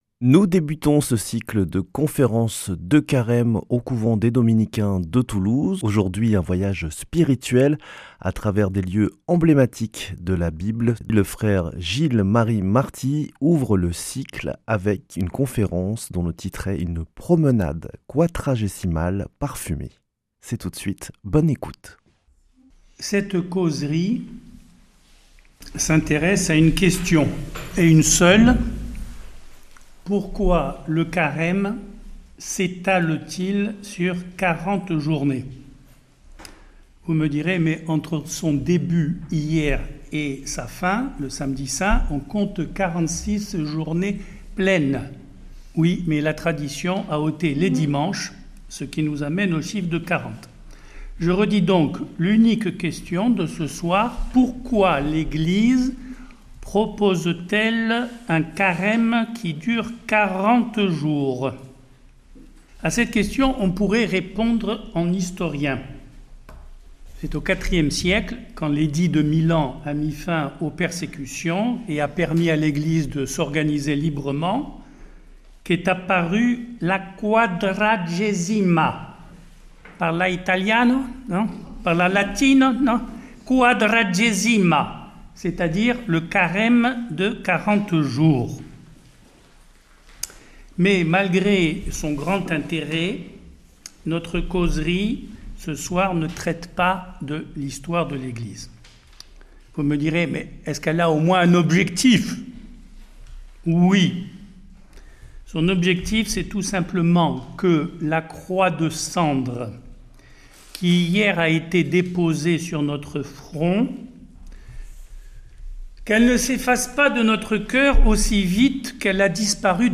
Conférence de carême des Dominicains de Toulouse